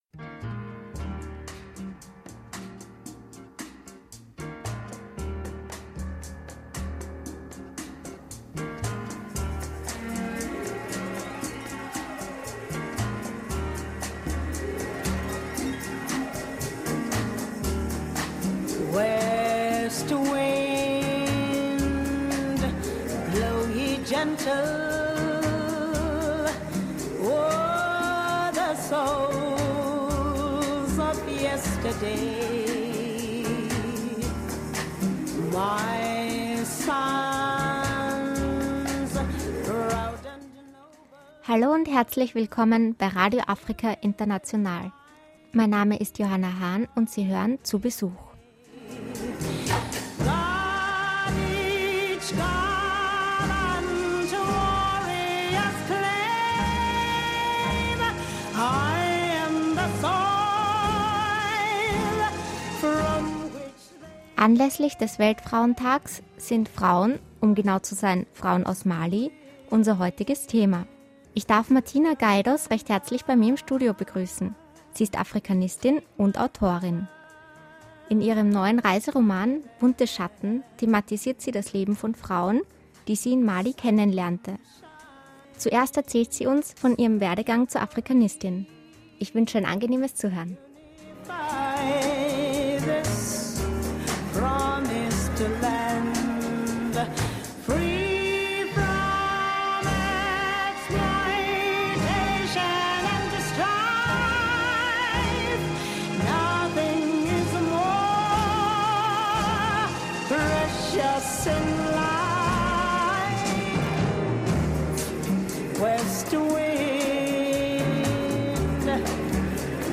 Das geesamte Gespräch, 61-Minuten, mit viel Musik aus Mali hier …